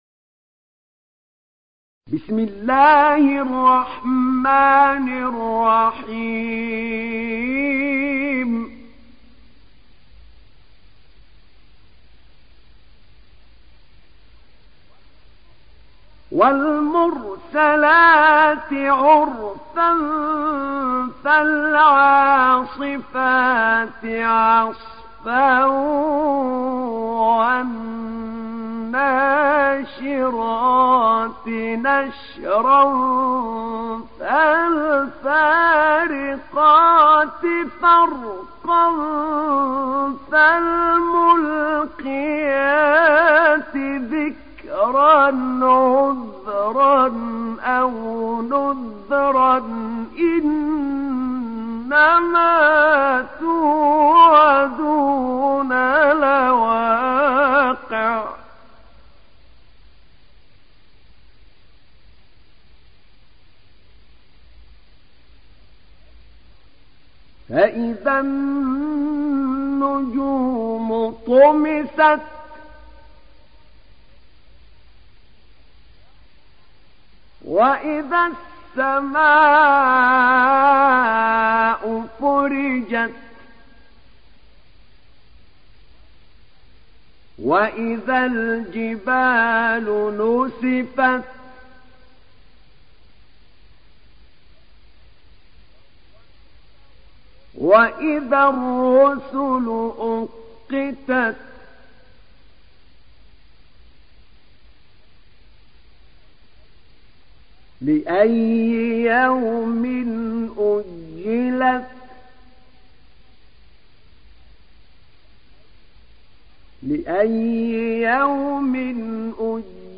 تحميل سورة المرسلات mp3 بصوت أحمد نعينع برواية حفص عن عاصم, تحميل استماع القرآن الكريم على الجوال mp3 كاملا بروابط مباشرة وسريعة